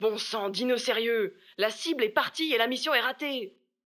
VO_ALL_EVENT_Temps ecoule_03.ogg